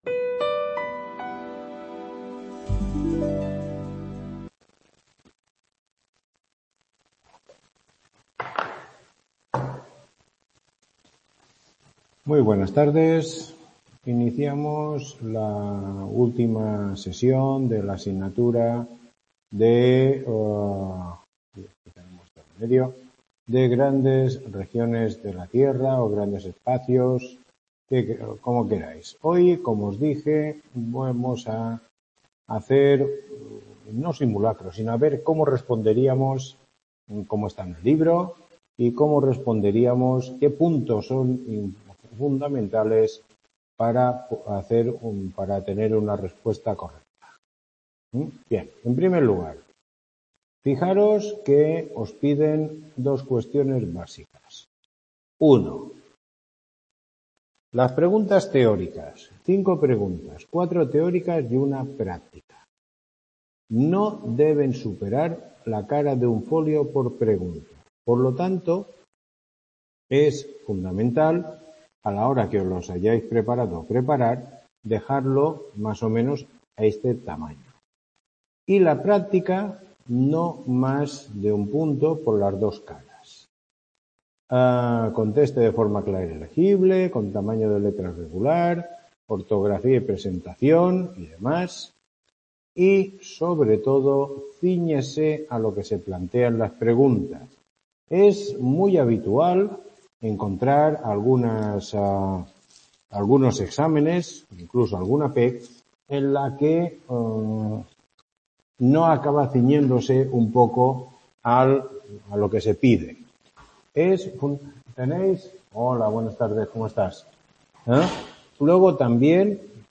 Tutoría 12